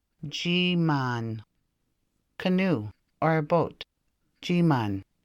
Anishinaabemowin Odawa: Jiimaan    [Jii maan]